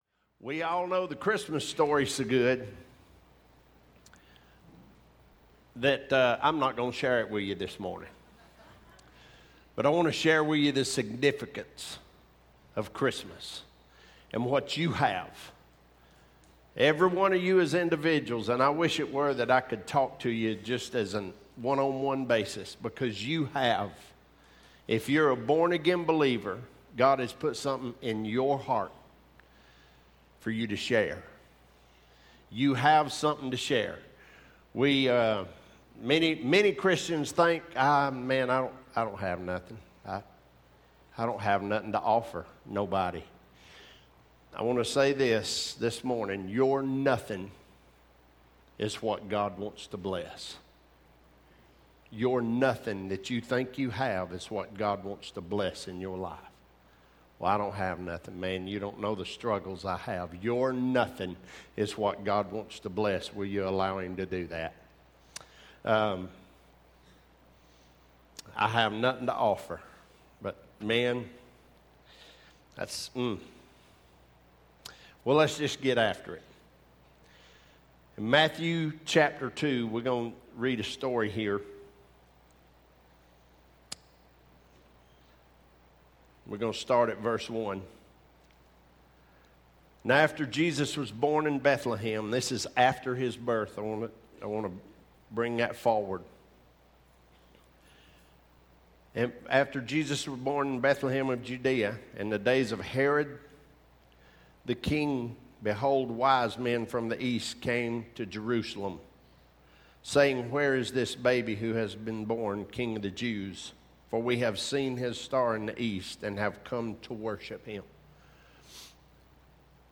Sermons | Living for the Brand Cowboy Church of Athens